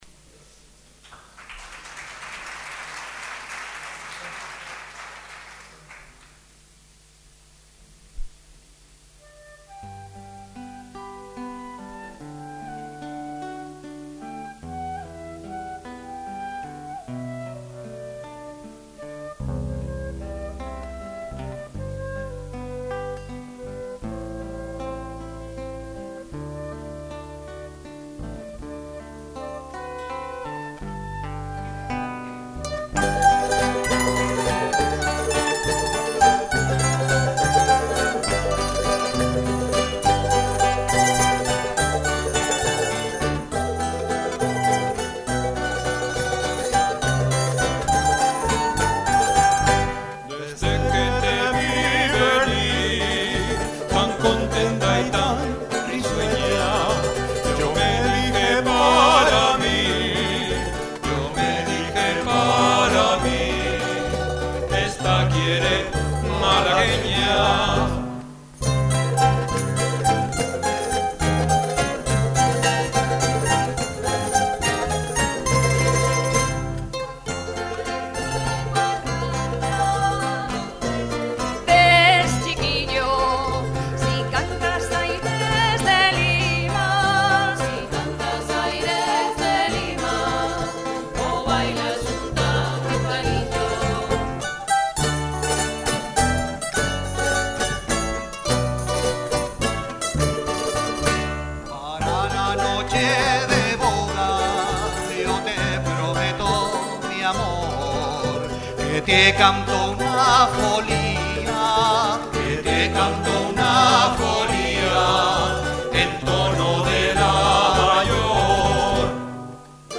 El jueves 16 de mayo, de 19:00 a 20:30 horas, se celebró en el salón de actos de la Facultad de Formación del Profesorado, con la participación del grupo "LasPalmeños ULPGC", siendo además su presentación oficial en la universidad con el nuevo nombre, y con nuevos temas.